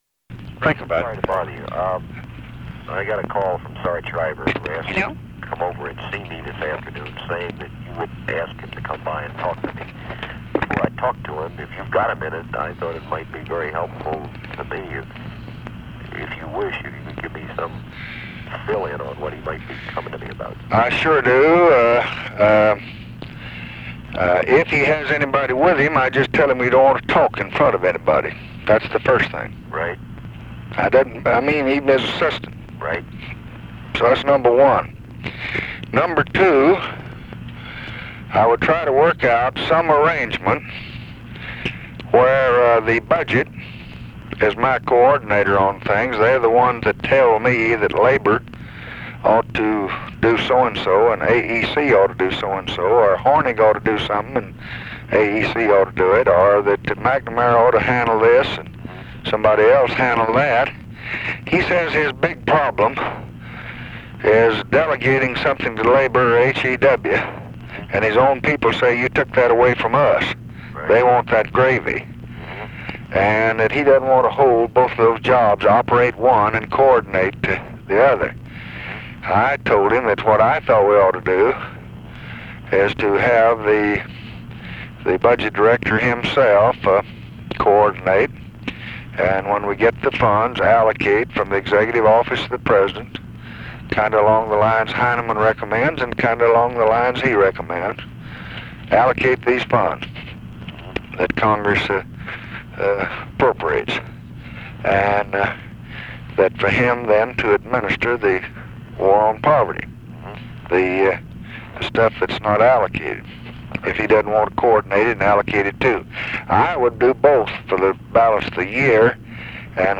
Conversation with CHARLES SCHULTZE and UNIDENTIFIED FEMALE, December 30, 1966
Secret White House Tapes